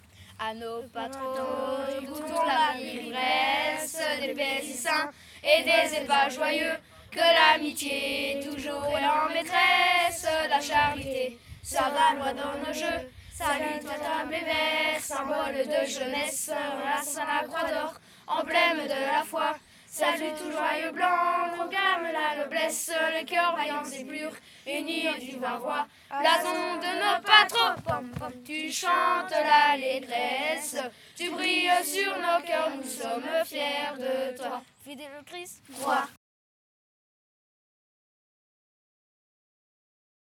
Genre : chant
Type : chant de mouvement de jeunesse
Interprète(s) : Patro de Bastogne
Lieu d'enregistrement : Bastogne
Enregistrement réalisé dans le cadre de l'enquête Les mouvements de jeunesse en chansons.